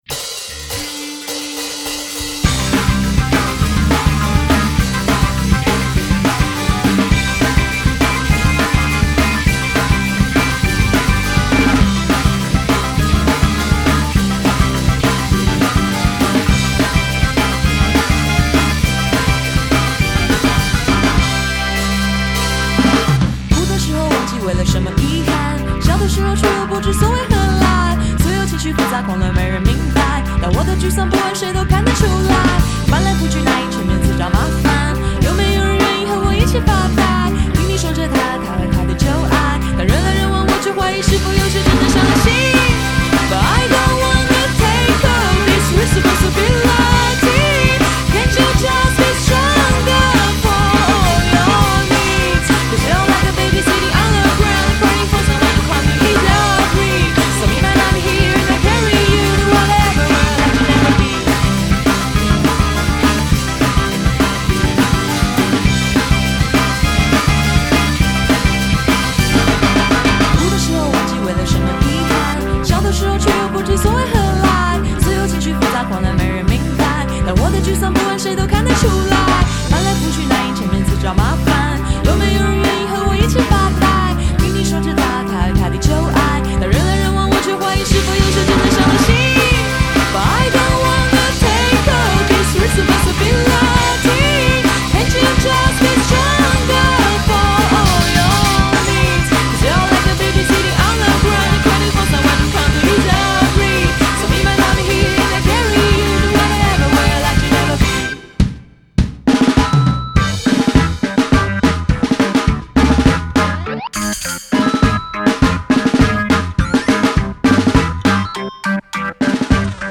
牵引出三人组合的熟成摇滚气势。